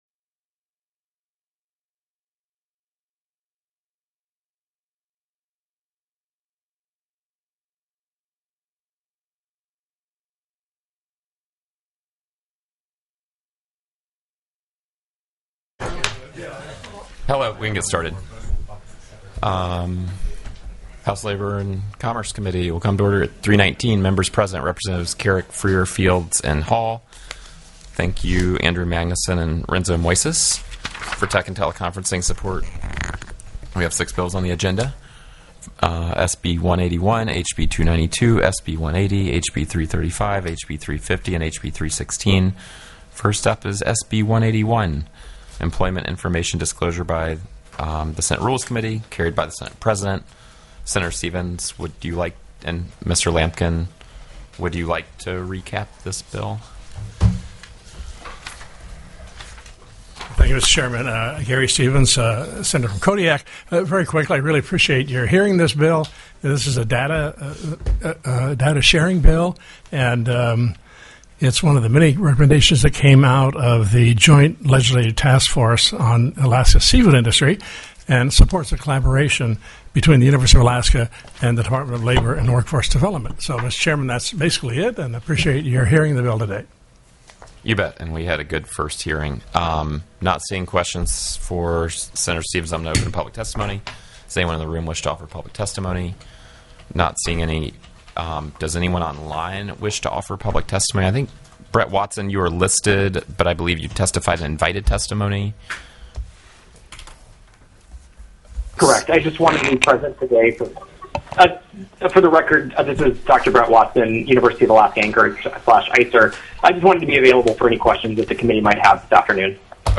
04/22/2026 03:15 PM House LABOR & COMMERCE
The audio recordings are captured by our records offices as the official record of the meeting and will have more accurate timestamps.